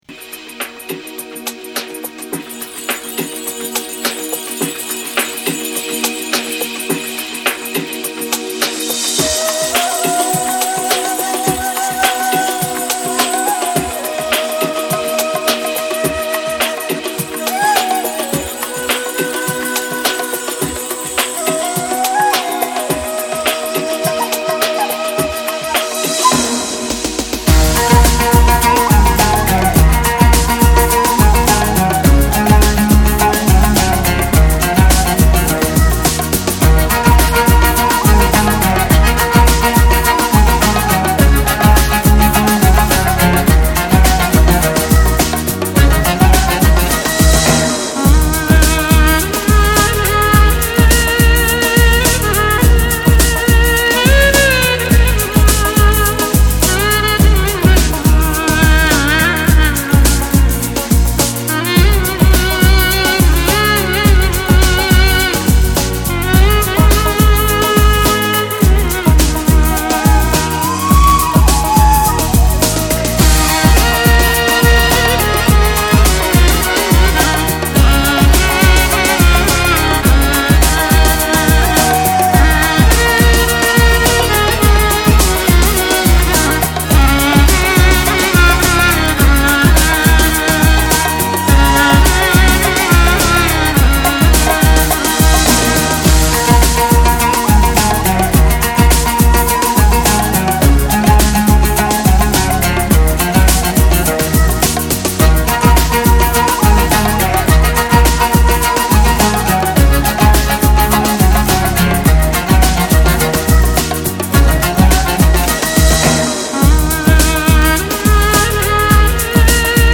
vostochnij_trans___magicheskij_duduk_z2_fm.mp3